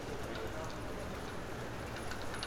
Paris_street1.L.wav